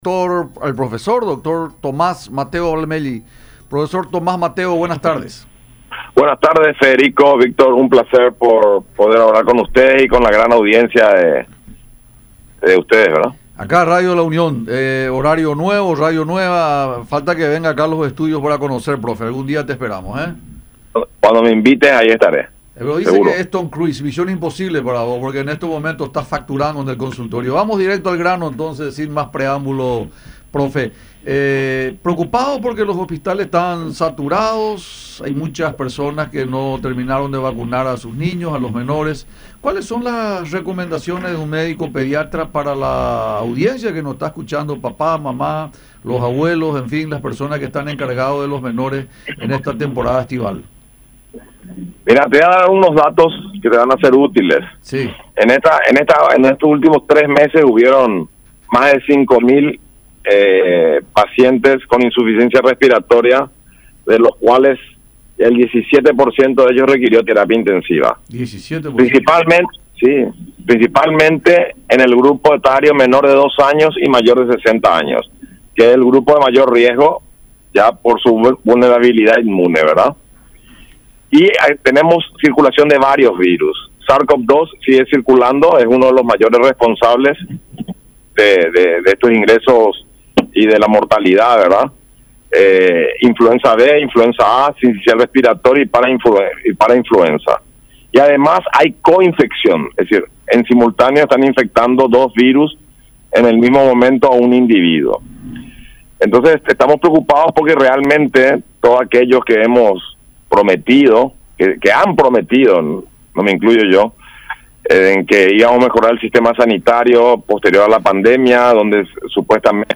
en charla con Francamente por Unión TV y radio La Unión.